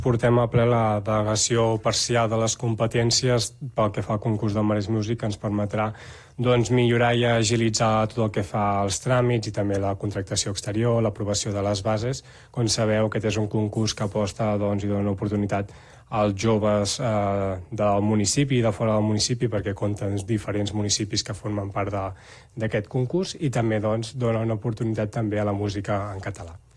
El regidor de Joventut, Aniol Canals, va destacar la importància del Maresmusic com a plataforma de suport als grups emergents.